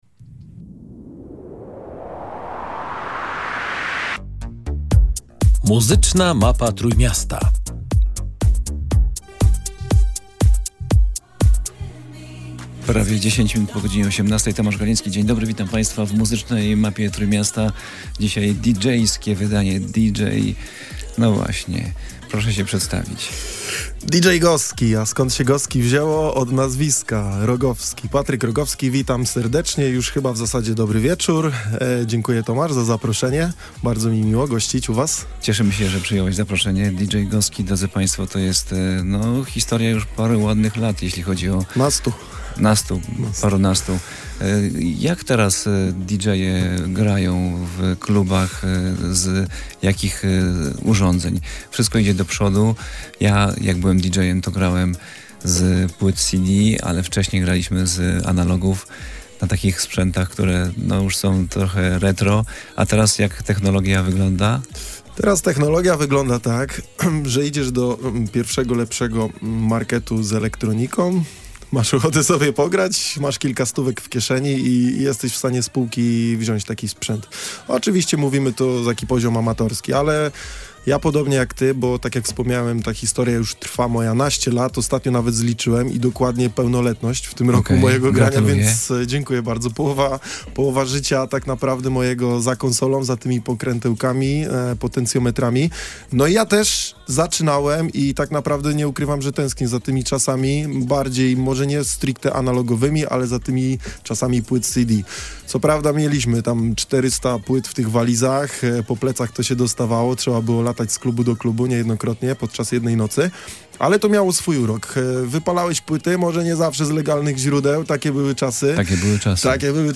Wakacyjne wydanie audycji „Muzyczna Mapa Trójmiasta” tym razem w tanecznych rytmach.